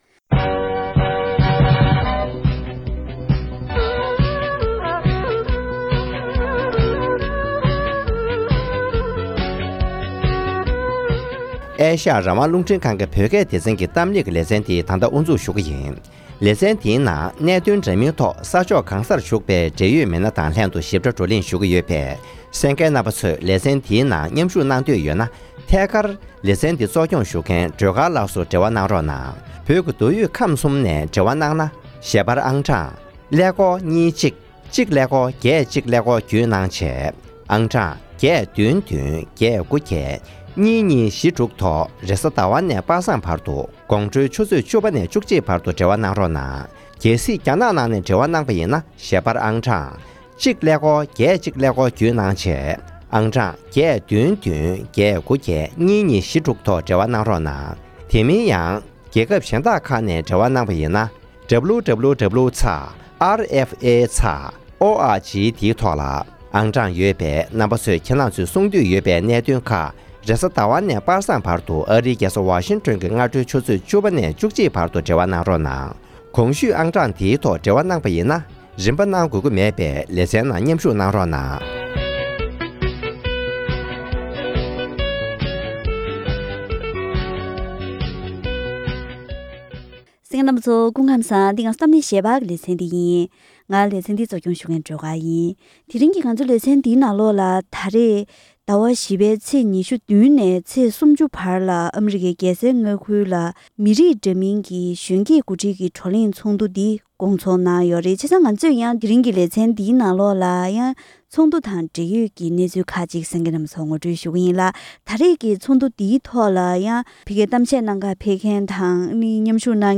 ད་ཐེངས་ཚོགས་འདུའི་ཐོག་མཉམ་ཞུགས་གནང་མཁན་བོད་མི་ཁག་ཅིག་དང་ལྷན་ཚོགས་འདུའི་ཐོག་བོད་དང་འབྲེལ་ཡོད་ཀྱི་གནད་དོན་ཐོག་བཀའ་མོལ་གང་གནང་གི་ཡོད་མིན་དང་ཚོགས་འདུའི་འབྲེལ་ཡོད་སྐོར་ལ་བཀའ་མོལ་ཞུས་པ་ཞིག་གསན་རོགས་གནང་།